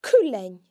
The non-slender L can also be heard in cuilean (a puppy):